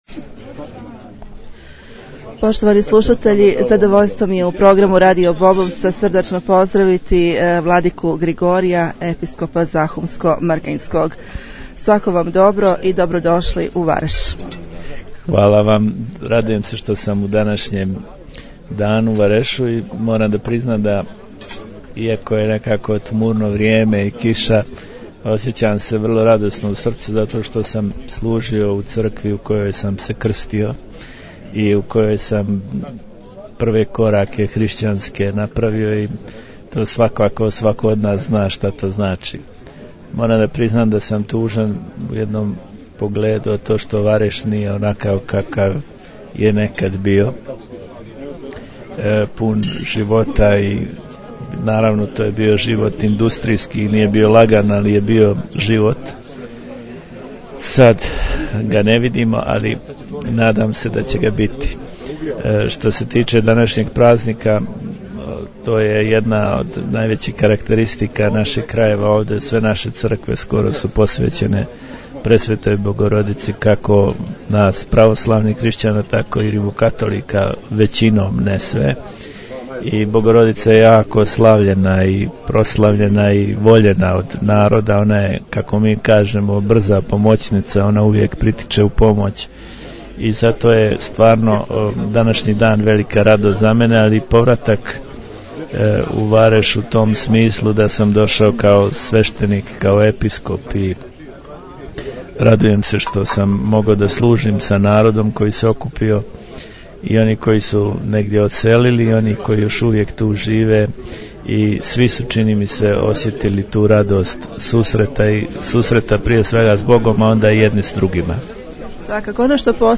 Vladika Grigorije - razgovor
Povodom obilježavanja hramovne slave u Varešu je bio Episkop zahumsko-hercegovački i primorski vladika Grigorije, poslušajte razgovor.....